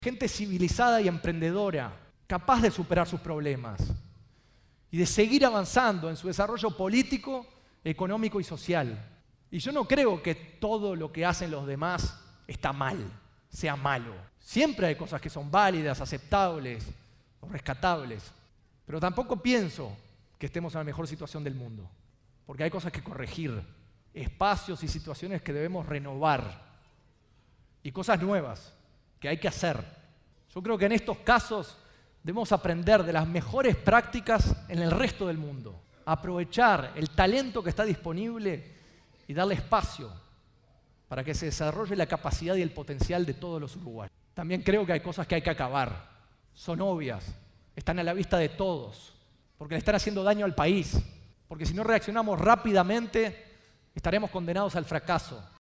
El empresario y precandidato a la Presidencia por el Partido Nacional, Juan Sartori, habló por primera vez ante el público en el Teatro Metro.